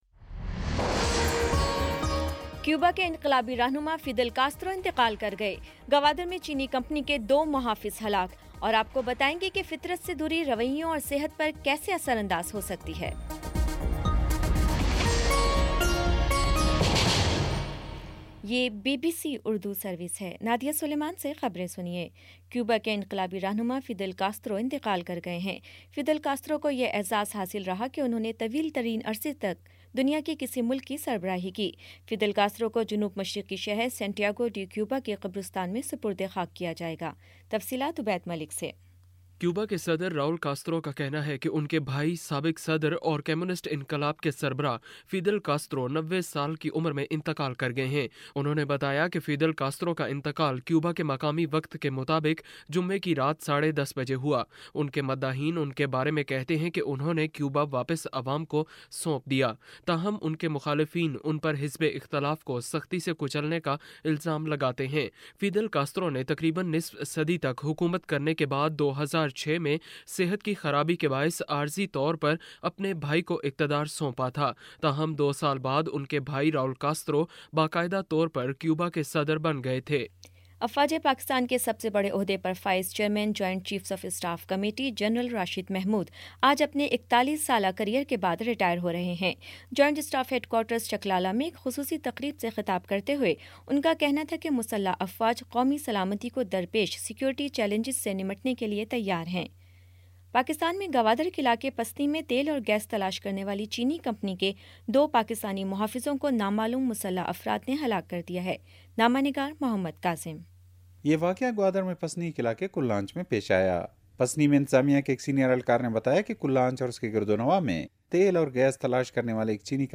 نومبر 26 : شام چھ بجے کا نیوز بُلیٹن